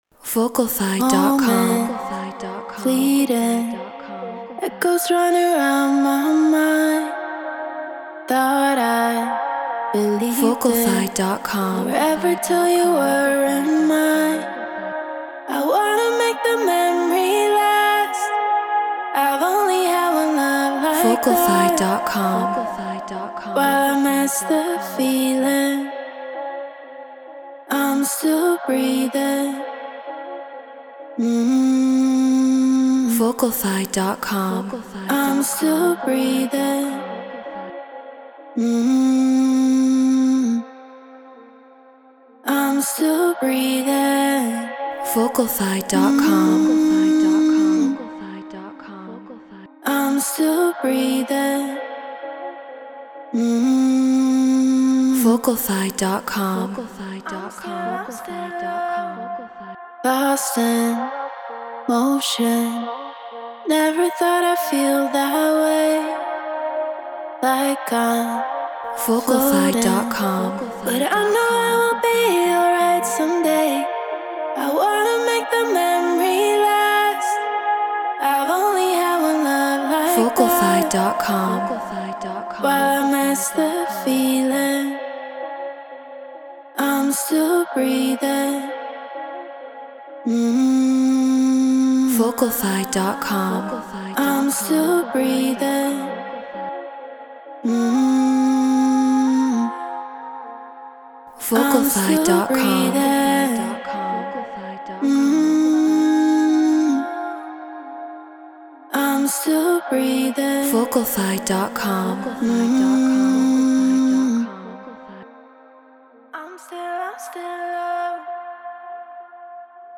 House 130 BPM C#maj
Shure KSM 44 Apollo Twin X Pro Tools Treated Room